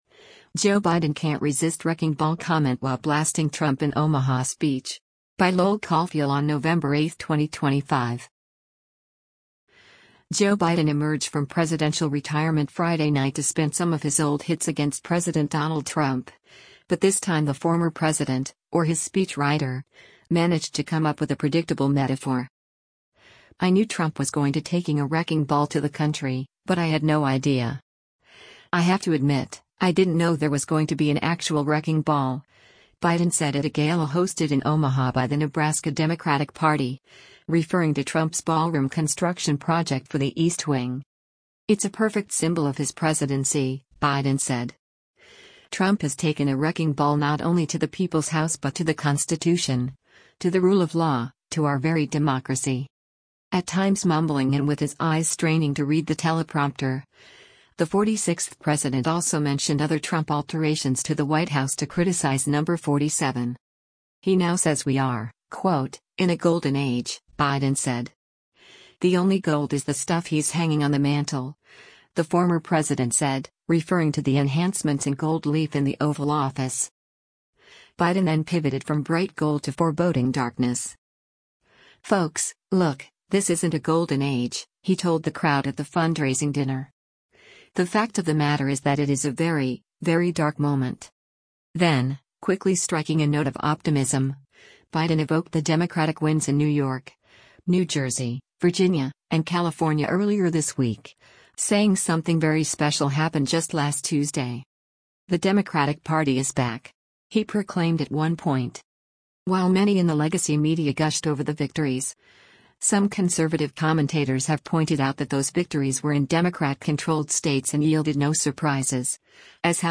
Former President Joe Biden speaks during the Ben Nelson Gala Friday, Nov. 7, 2025, in Omah
At times mumbling and with his eyes straining to read the teleprompter, the 46th president also mentioned other Trump alterations to the White House to criticize Number 47.
“Folks, look, this isn’t a golden age,” he told the crowd at the fundraising dinner.
“The Democratic Party is back!” he proclaimed at one point.